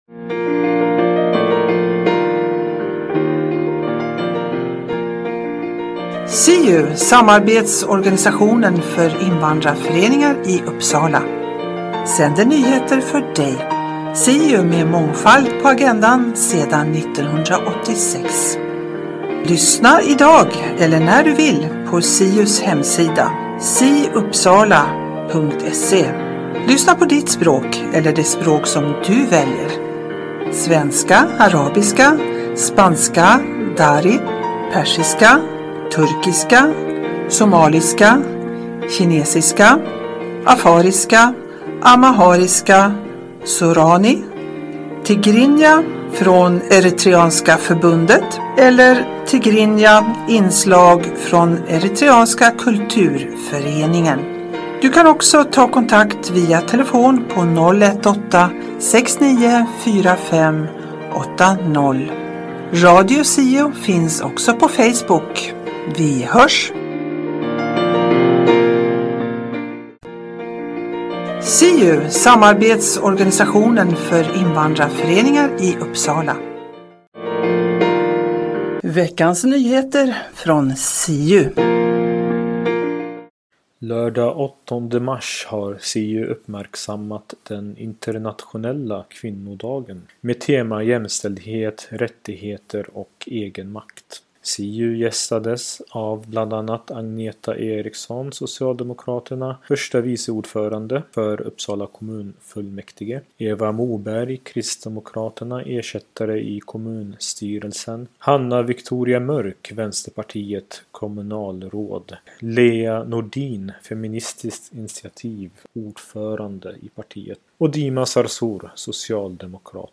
Siu-programmet på svenska innehåller SIU:s nyheter, Nyheter Uppsala och Riksnyheter. Berika din fritid med information och musik.